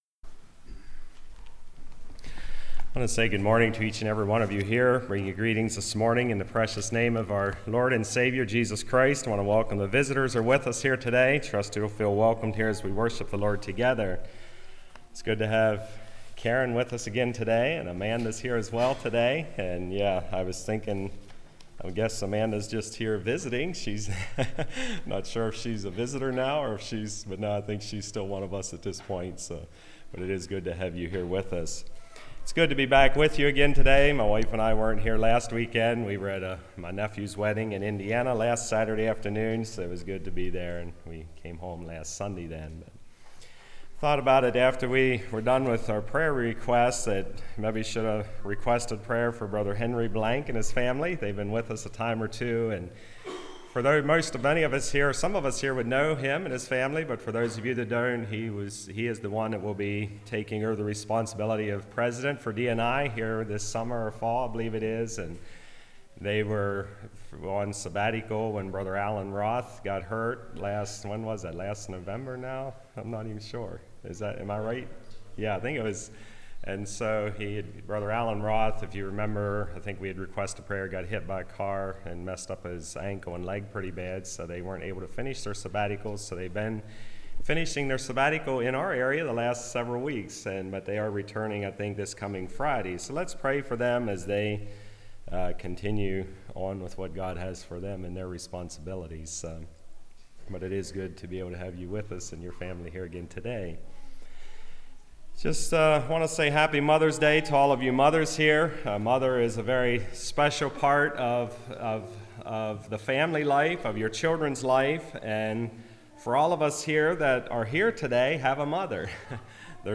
Passage: 1 Timothy 1:1-7 Service Type: Message